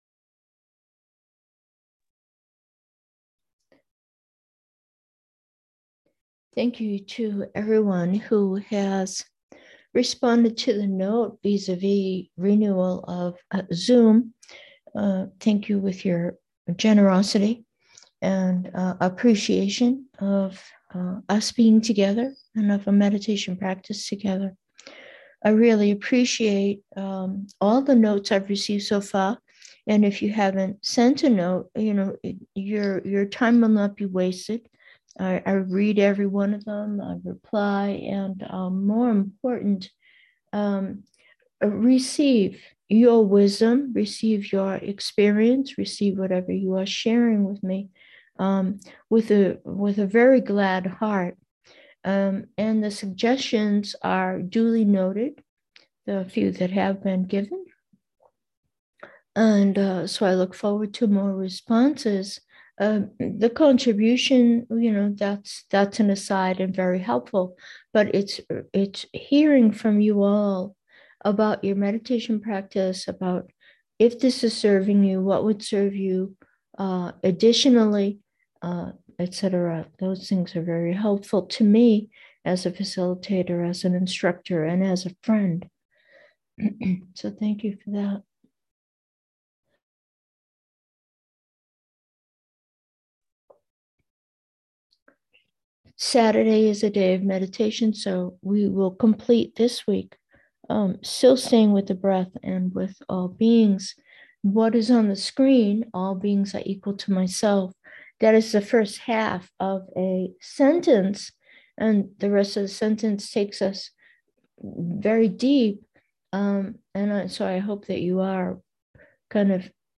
Meditation: breath, harmony